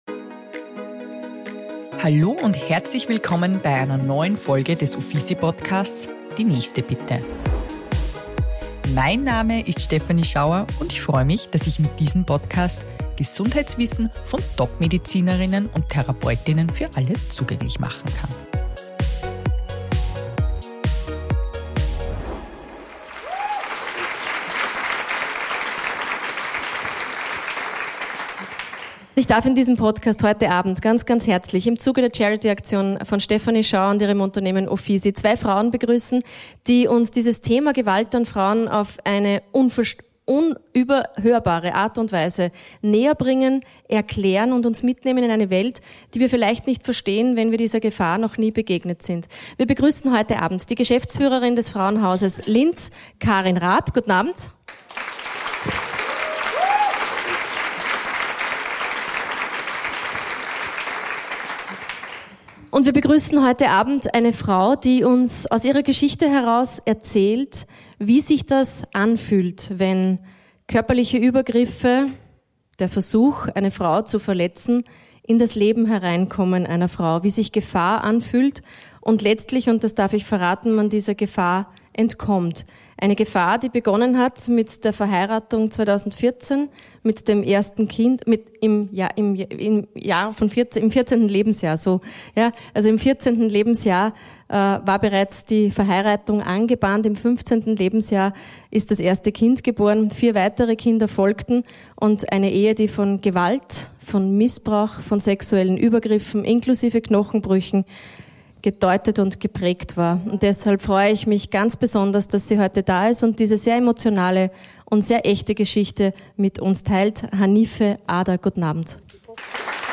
OFFISY Charity LIVE Podcast